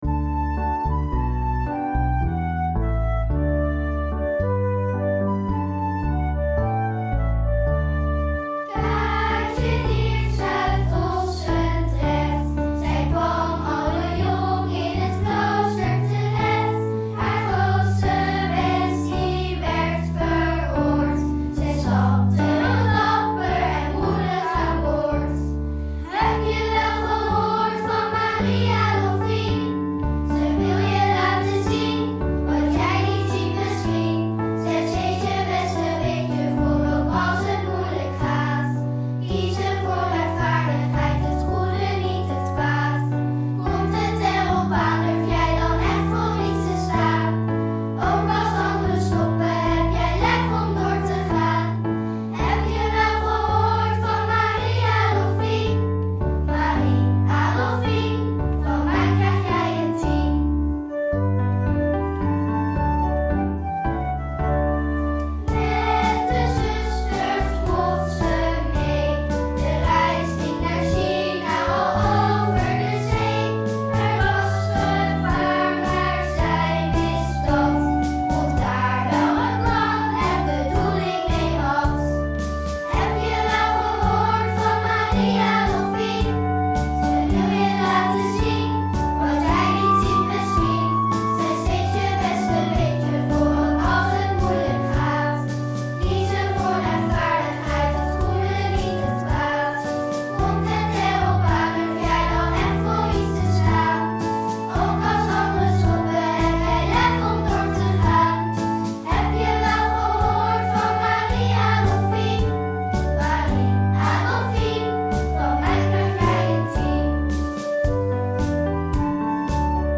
Lied van Marie Adolphine (kinderkoor)
4-lied_van_marie_adolphine_kinderkoor.mp3